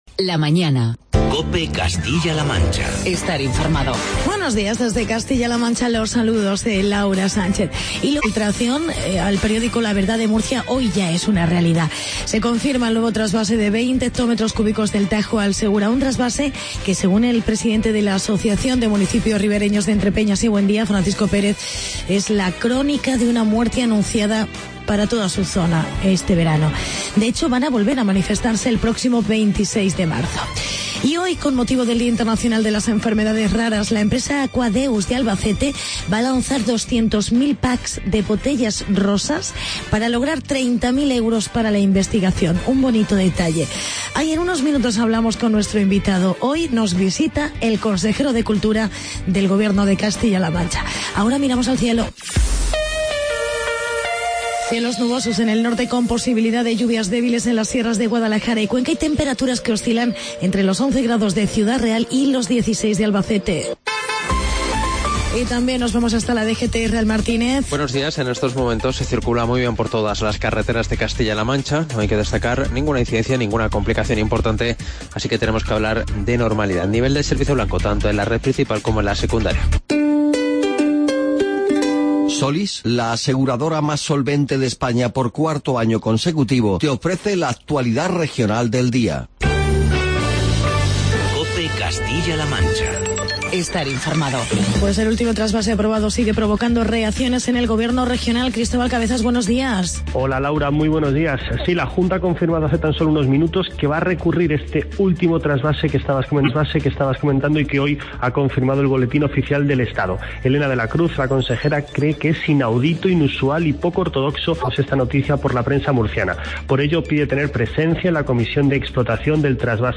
Actualidad y entrevista con el Consejero de Educación, Cultura y Deportes, Angel Felpeto. Plan Estrátegico de Cultura.